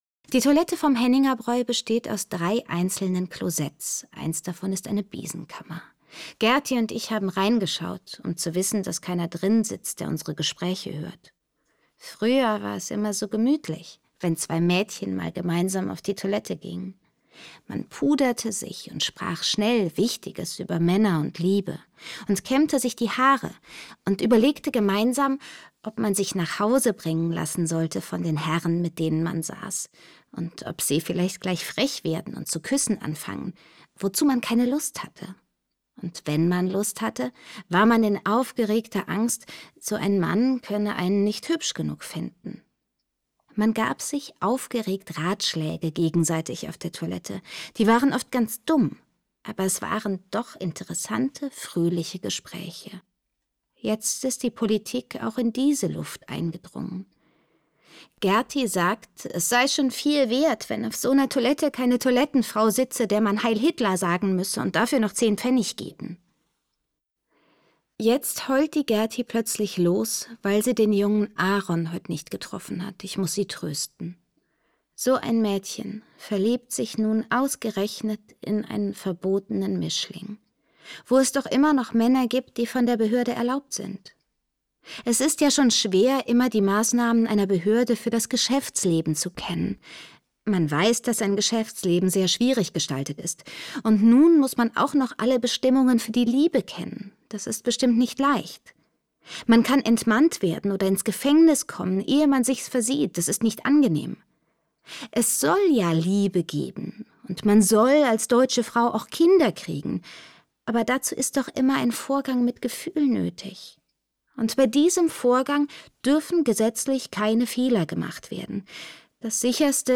Irmgard Keun: Nach Mitternacht (4/15) ~ Lesungen Podcast